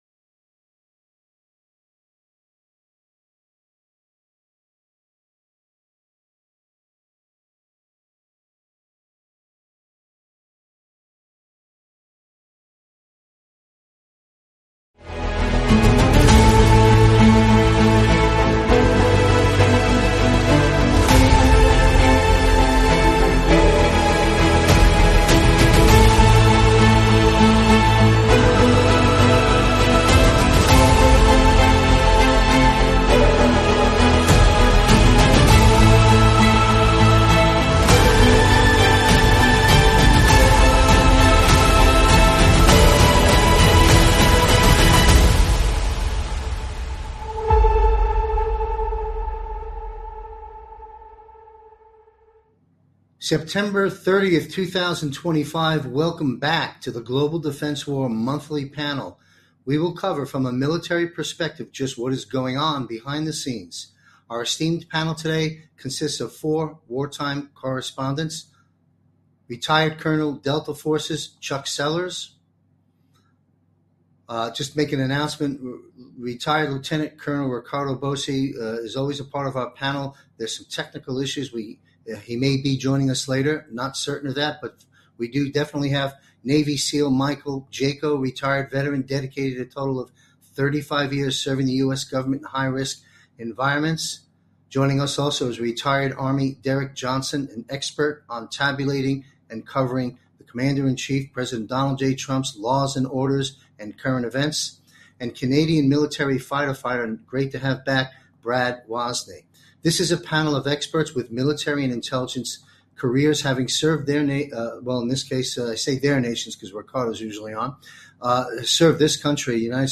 The Global Defense War Monthly panel, featuring retired military and intelligence experts, delved into pressing global issues. They discussed James Comey's indictment, potential military tribunals, and the state of Christianity.